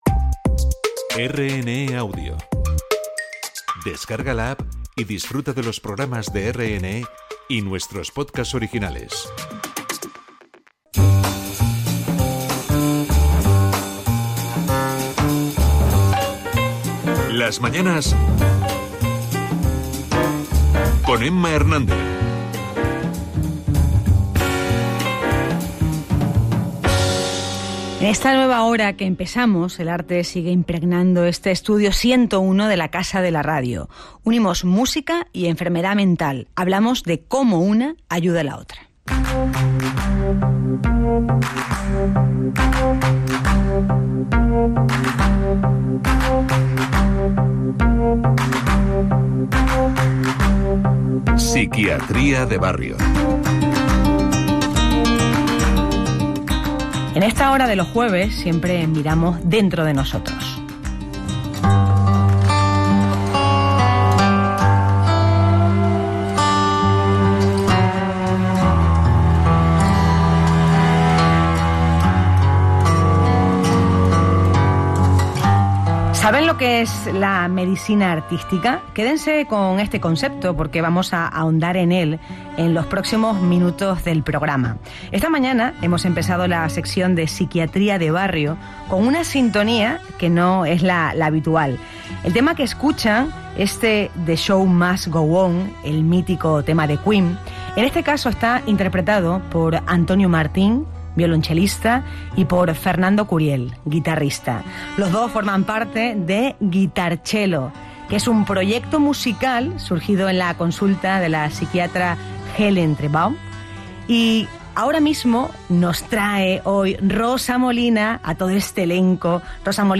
Hablamos del trasplante de hígado de cerdo a humano, que revive un proyecto para injertar órganos porcinos en Murcia. Escuchamos a Beatriz Domínguez-Gil, directora general de la Organización Nacional de Trasplantes española.